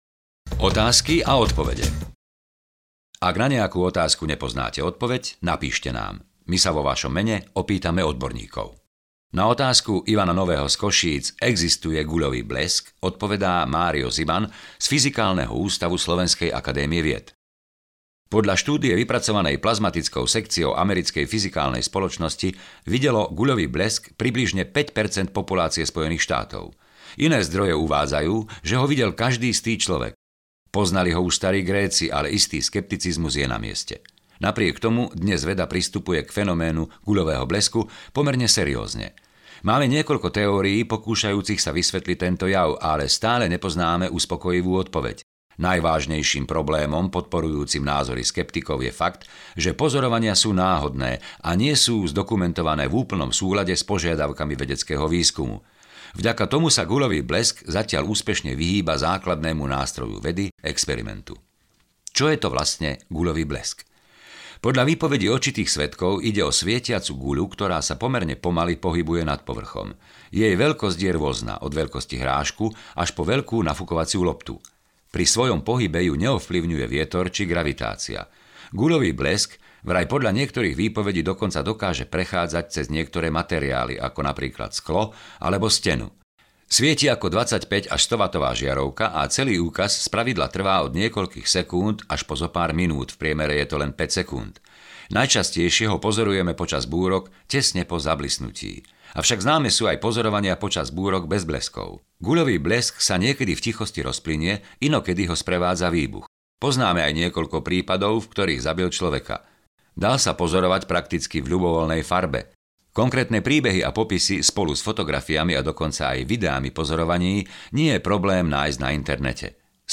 Do svojho MP3 prehrávača si môžete aj tento mesiac stiahnuť bezplatné hodinové čítanie z najnovšieho čísla časopisu Quark.